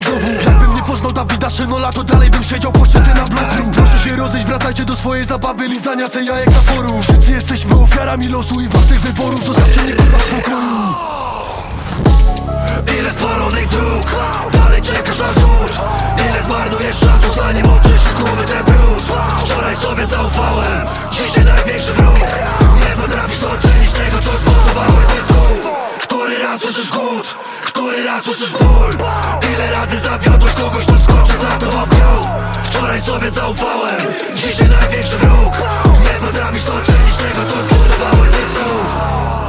Kategoria Rap/Hip Hop